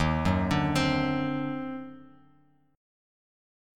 D#mM13 chord